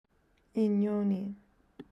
Listening Pronunciation Activity